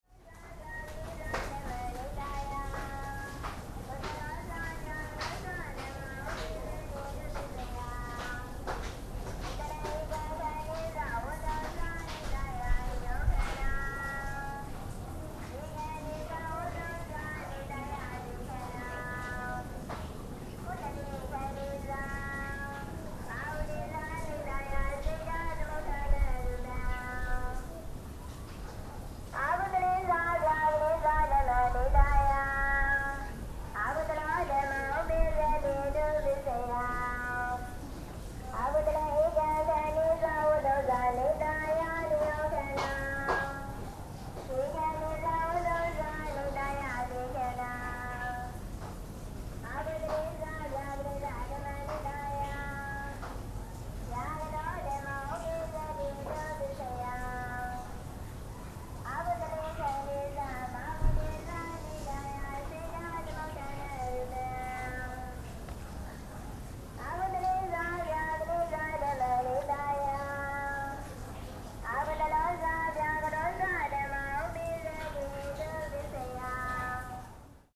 Field Recordings from Vietnam, Myanmar and Japan.
I was half way to the top of the hill. I heard a sutra read by woman from speaker. Some children passed me by.
Recorded on December 2004, Sagaing, Myanmar.
sagaing-hill-december-2004-sagaing-myanmar-excerpt.mp3